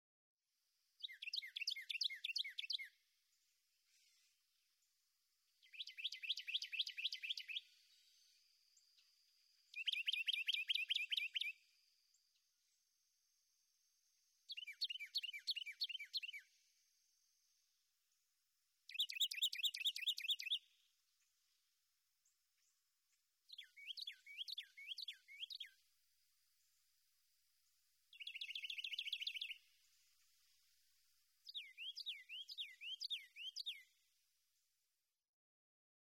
Carolina wren
♫109. Eight male songs, just one example of each, excerpted from a longer sequence in which each of the songs was repeated many times; these eight songs represent perhaps a quarter of the songs he knows. A commonly used mnemonic for the song is tea-kettle tea-kettle tea-kettle, or lib-er-ty, lib-er-ty, lib-er-ty.
Audubon Corkscrew Swamp Sanctuary, Naples, Florida.
109_Carolina_Wren.mp3